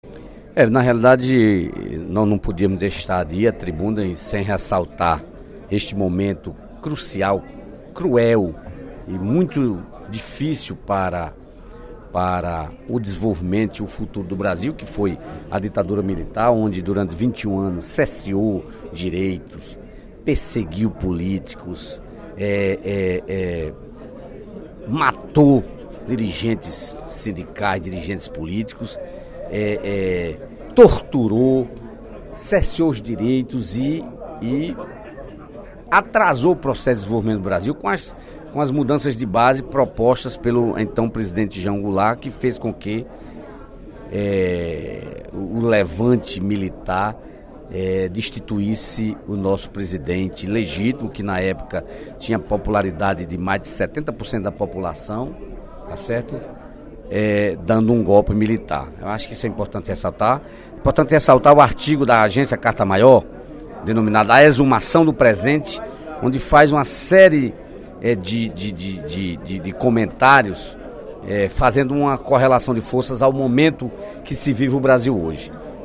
O deputado Dedé Teixeira (PT) fez pronunciamento no primeiro expediente da sessão plenária desta terça-feira (1º/04) para destacar artigo publicado no portal Carta Maior sob o título “A exumação do presente”, escrito por Saul Leblon, sobre o Golpe Militar.